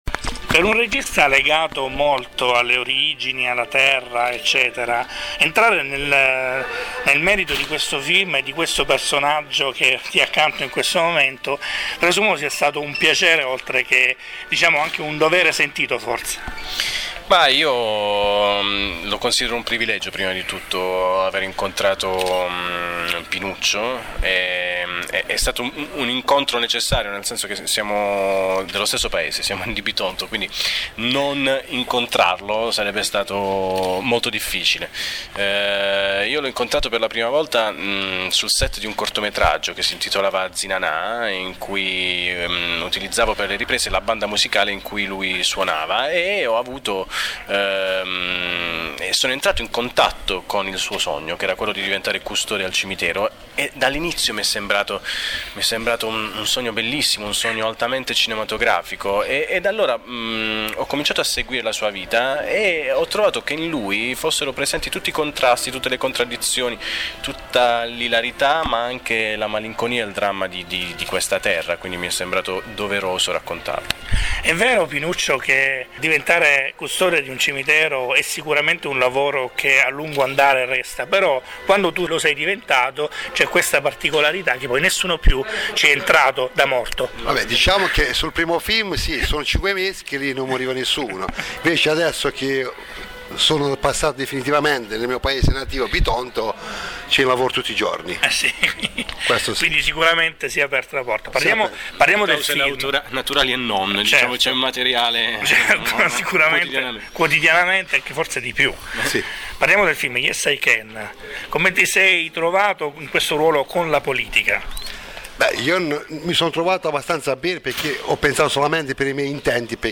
È il film rivelazione dell’anno e al Maxicinema Andromeda di Brindisi arriva anche il cast.